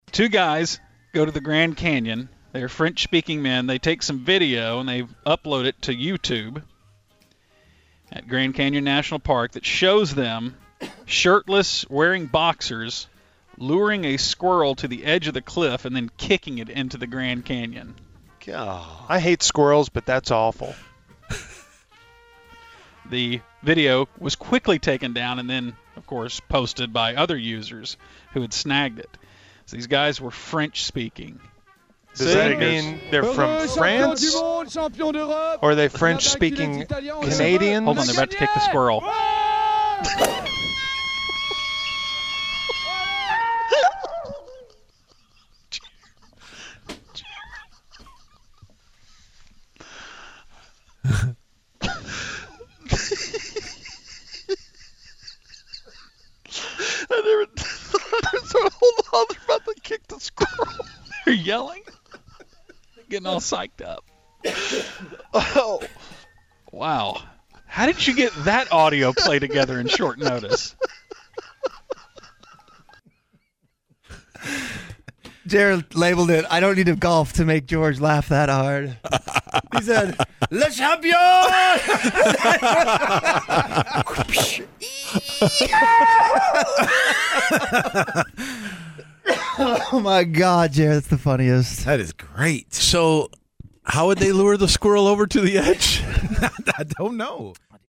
The Musers laugh about a French man who lures and kicks a squirrel off the Grand Canyon